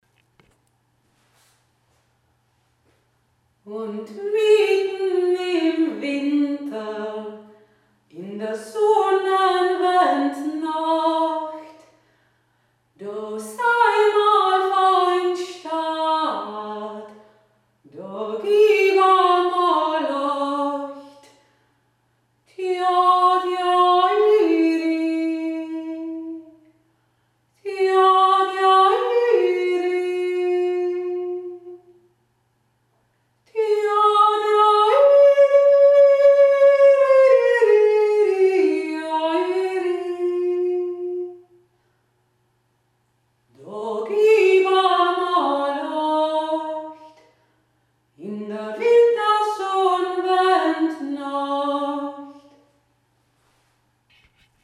1. Stimme
1. Stimme Mittn im Winter (2.0 MB)